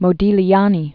(mō-dēlēnē, mōdē-lyänē), Amedeo 1884-1920.